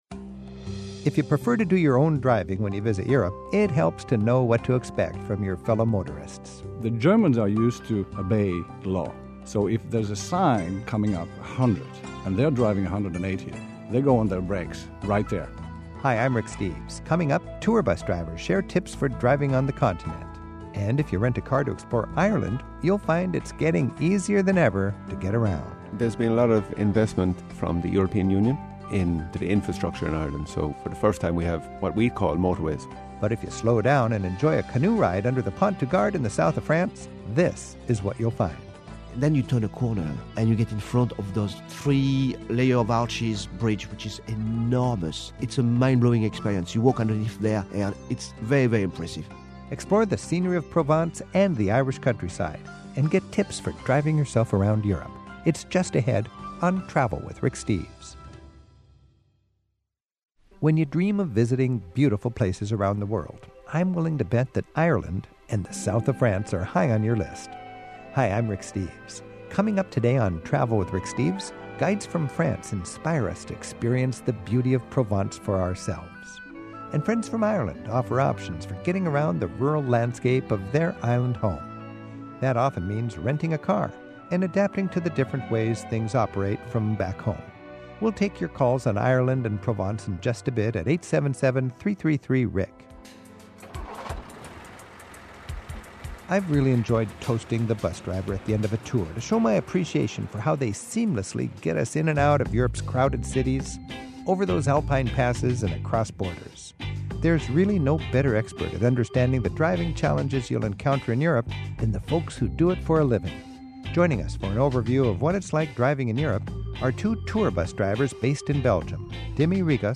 My Sentiment & Notes 367a Driving in Europe; Getting Around Ireland; Exploring Provence Podcast: Travel with Rick Steves Published On: Sat May 11 2024 Description: A pair of tour-bus drivers offer their expert tips on what to expect when navigating the highways of Europe. Then we discuss ideas for exploring two of Europe's most scenic regions: the lush Irish countryside and the floral landscapes and villages of southern France — and pop into a Dublin pub for a wee sing-along.